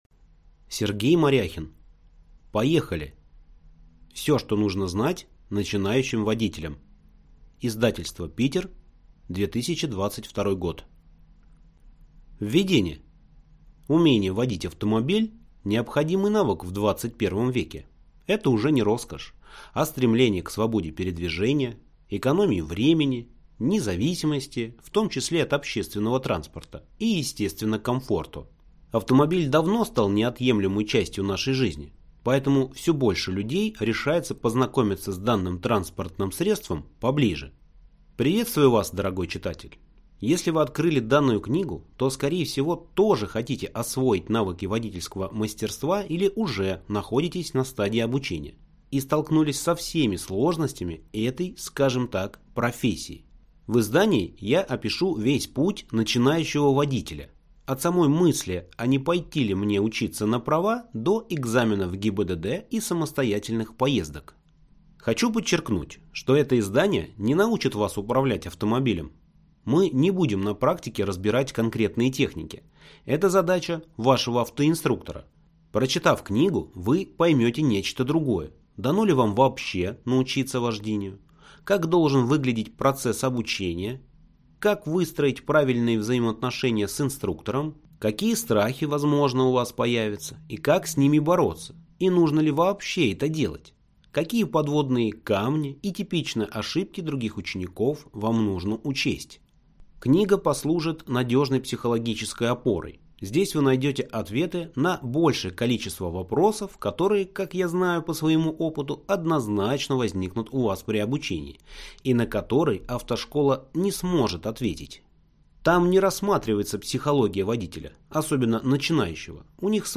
Аудиокнига Поехали! Все, что нужно знать начинающим водителям | Библиотека аудиокниг